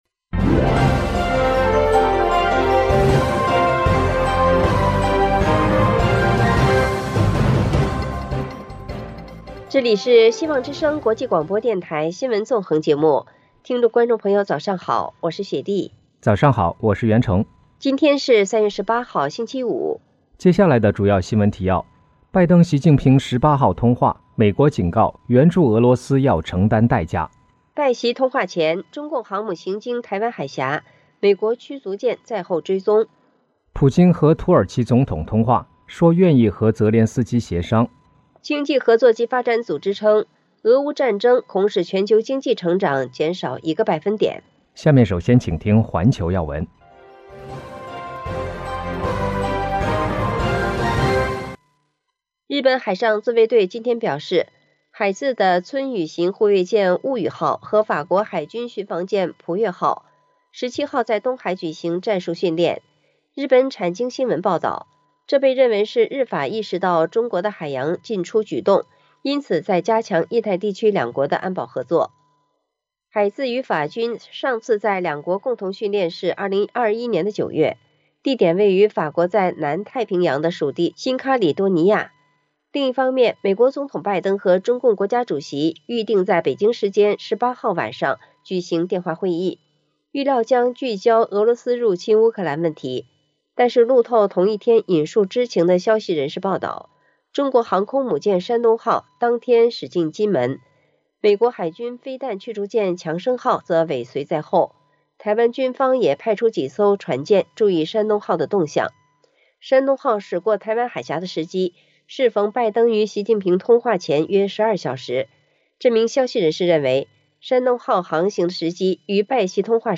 G7指侵乌战犯将被究责 美国正搜集罪证【晨间新闻】